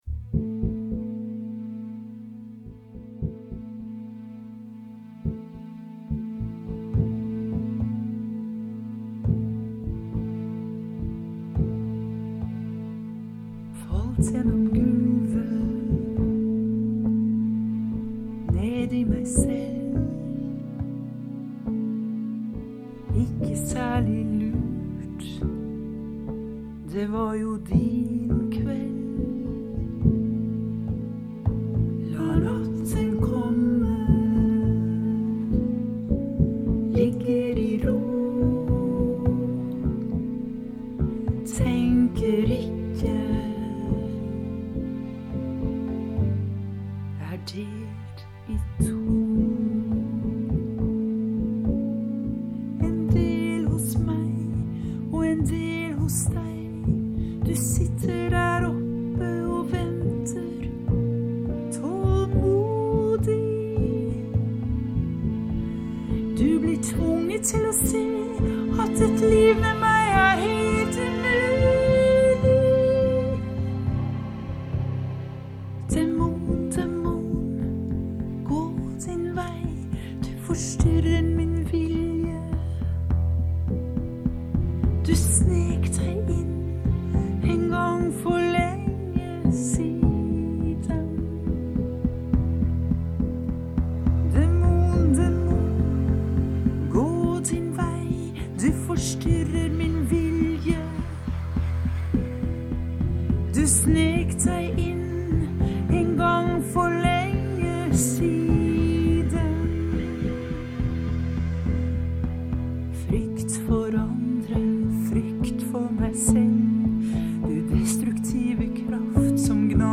Det blir vakker og røff musikk om hverandre i en dansekonsert egnet til både å overraske og åpne sansene.